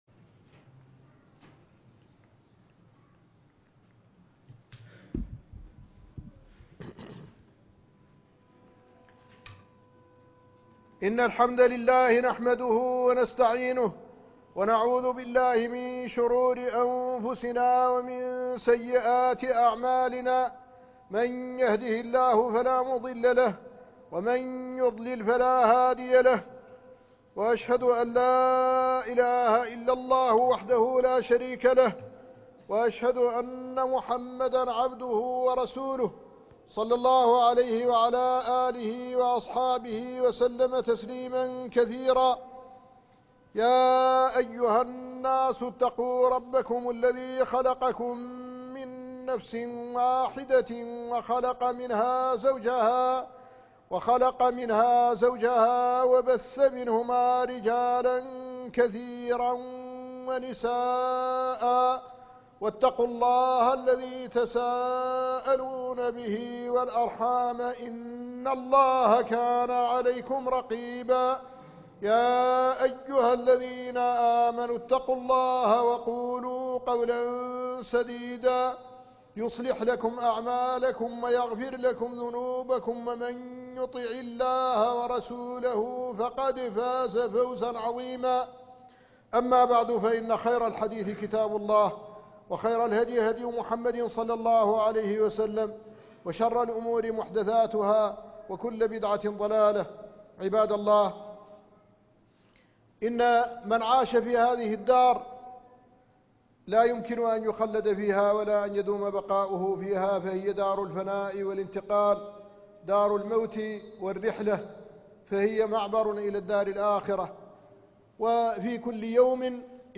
ماذا أعددت ليوم الرحيل ؟ خطبة الجمعة من جامع عبد الله بن ياسين